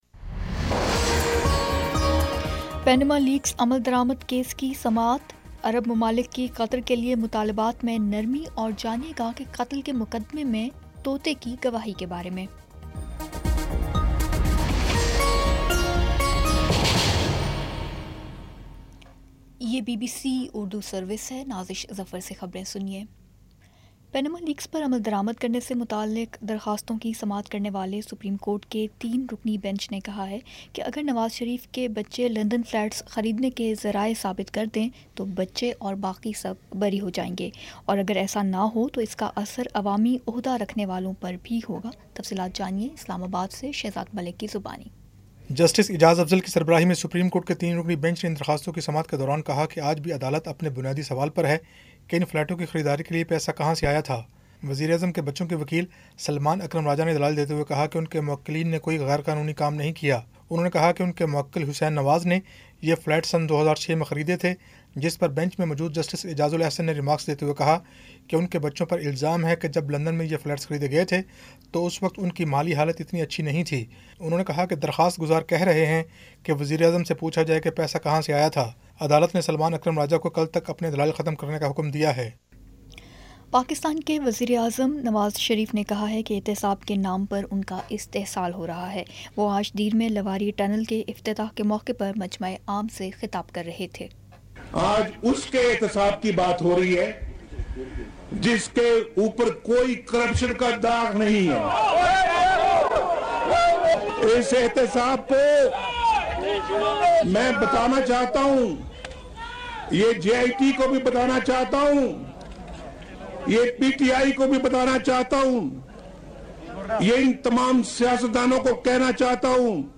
جولائی 20 : شام چھ بجے کا نیوز بُلیٹن